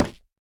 Minecraft Version Minecraft Version 1.21.5 Latest Release | Latest Snapshot 1.21.5 / assets / minecraft / sounds / block / nether_wood_trapdoor / toggle4.ogg Compare With Compare With Latest Release | Latest Snapshot
toggle4.ogg